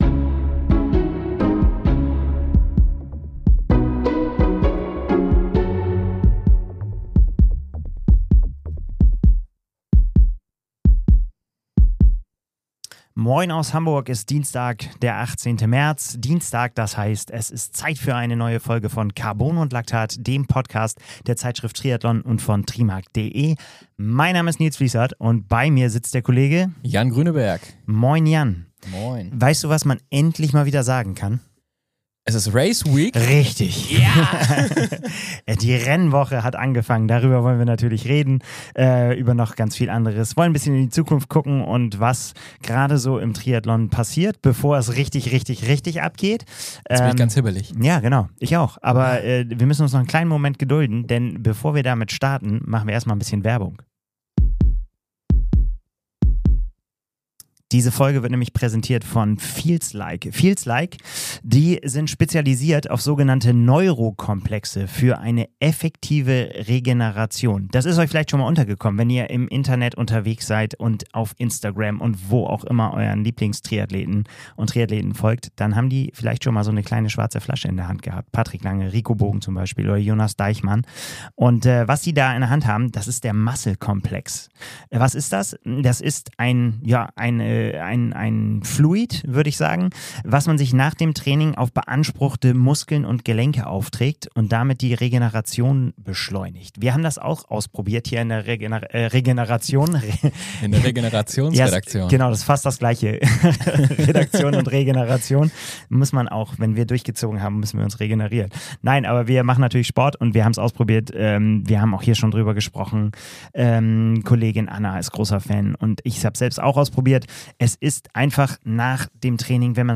Zwei Experten aus der Redaktion sprechen über das aktuelle Triathlongeschehen.